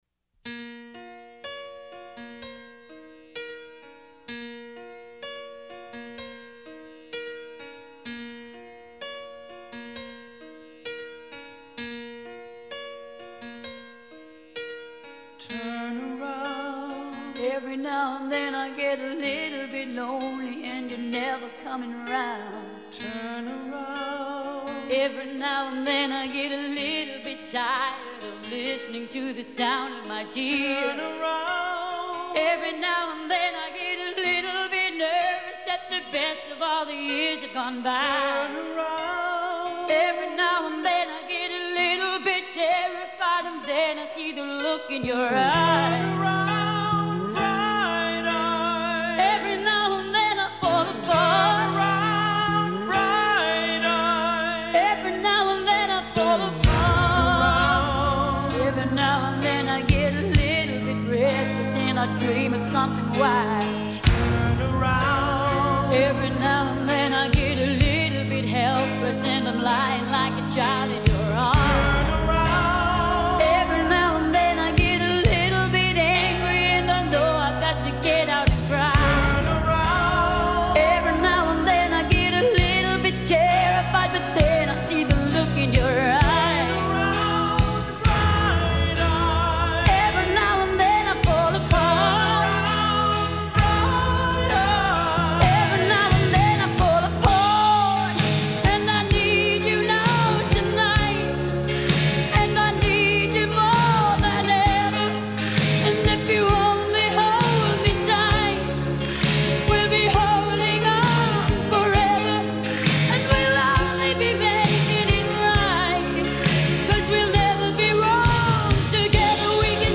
после чего её голос приобрел лёгкую хрипотцу.